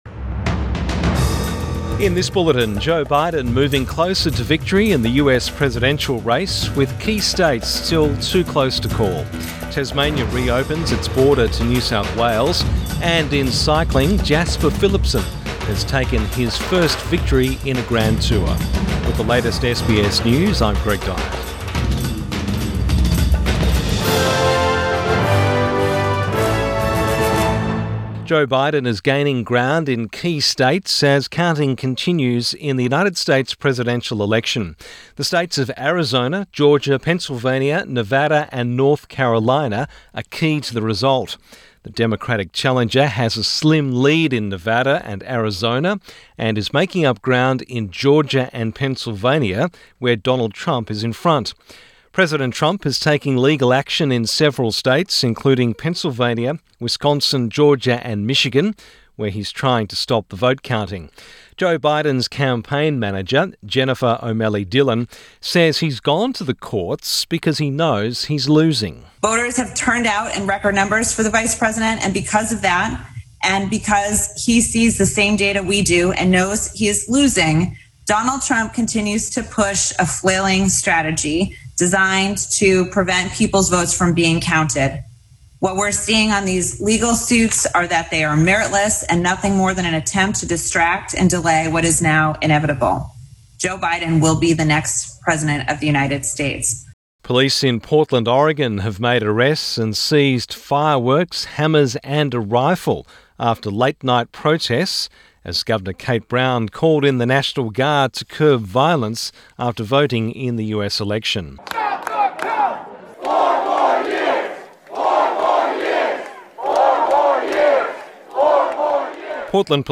AM bulletin 6 November 2020